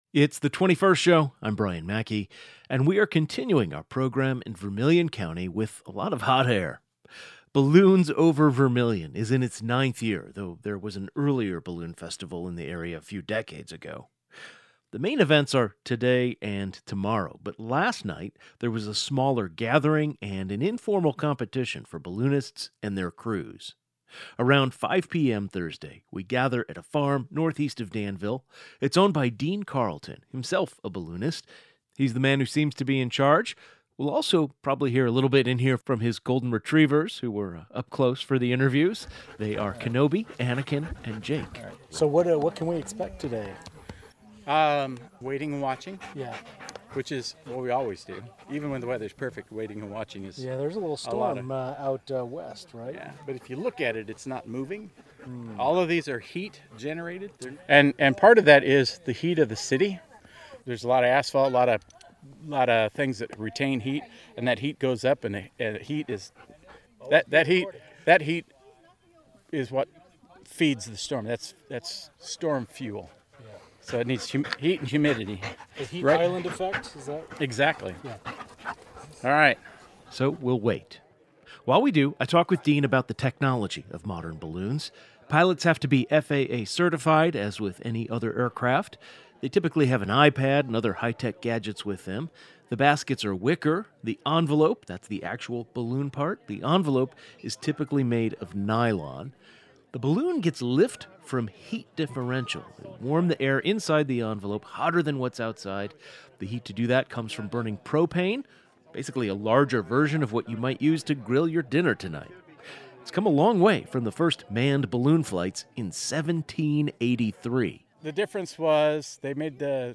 On the Friday, July 11, 2025 edition of The 21st Show, we took our program on the road to Vermilion County, which sits on the Illinois/Indiana border.